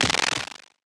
tesla-turret-beam-deflection-3.ogg